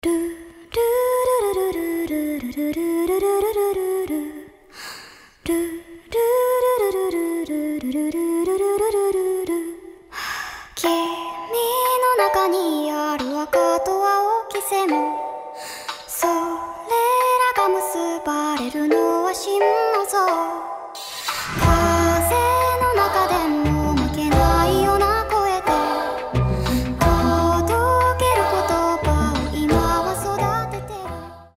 • Качество: 320, Stereo
милые
спокойные
японские